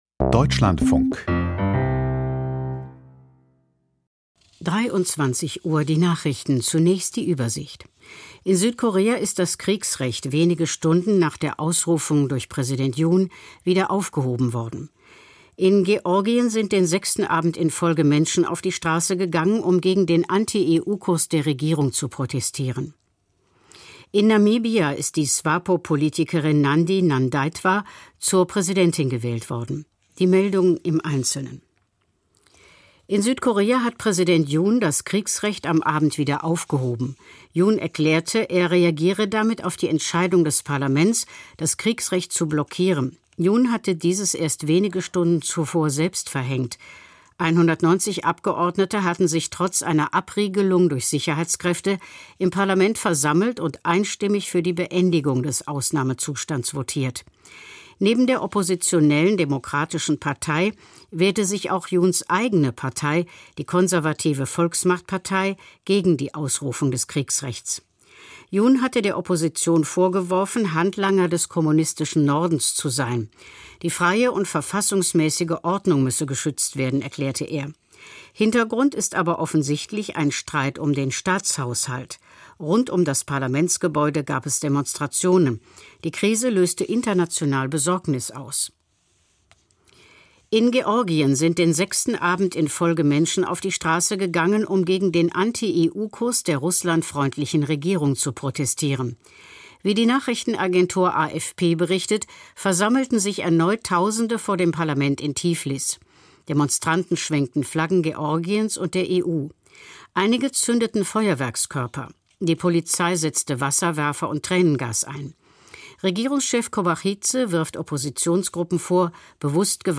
Die Nachrichten 9,415 subscribers updated 1h ago اشتراک مشترک پخش پخش کردن اشتراک گذاری علامت گذاری همه پخش شده(نشده) ...